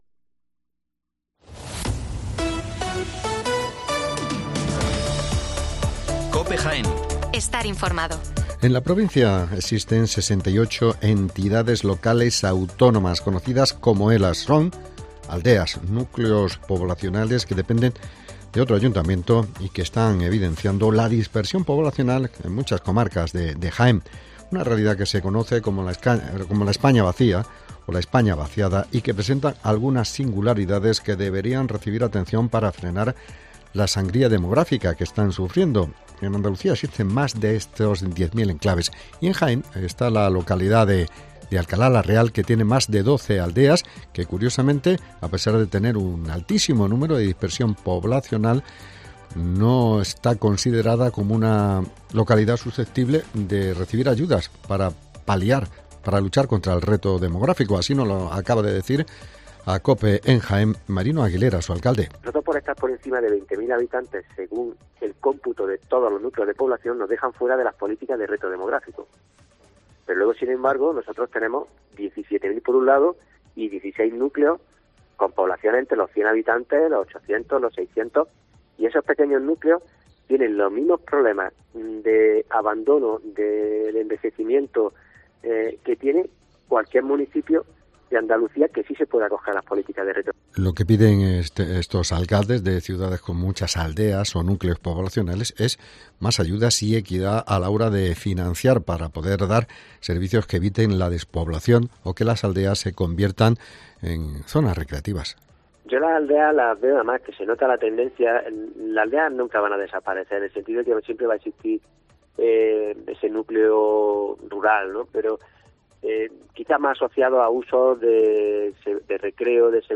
Las noticias locales